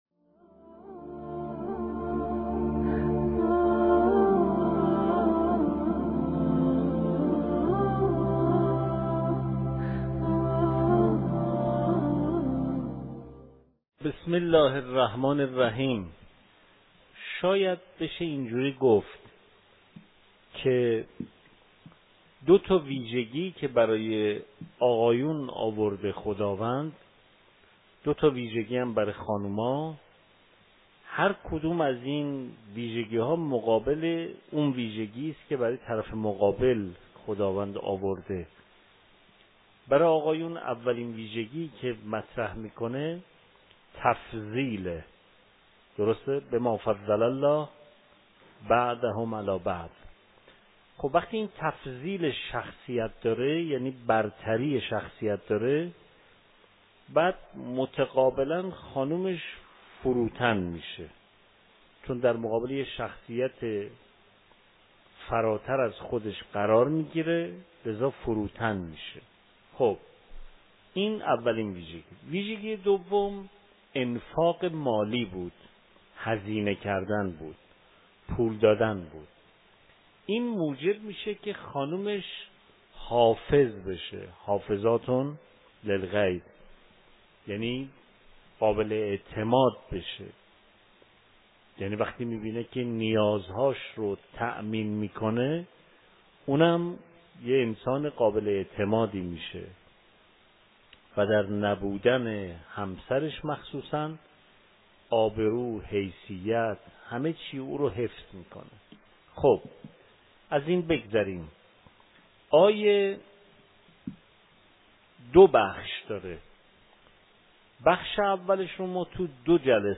مکان: دارالتفسیر حرم مطهر رضوی